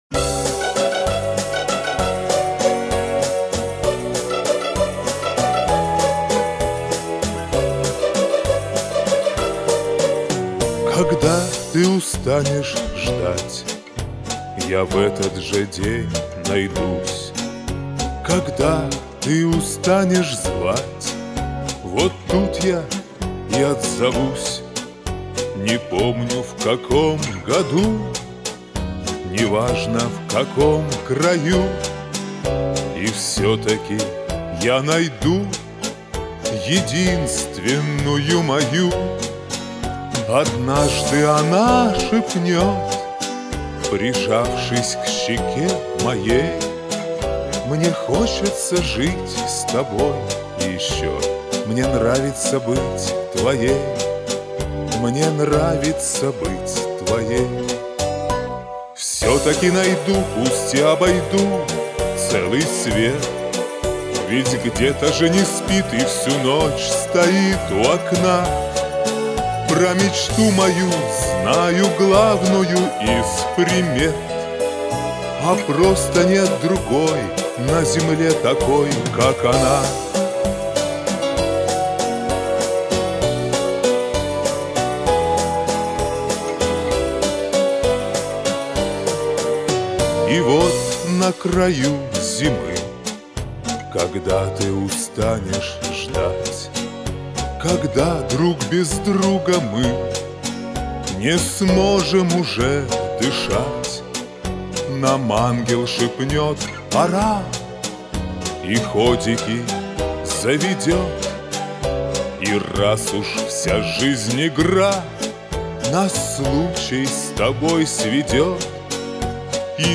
Истину глаголите, не хватает лёгкости и изящества!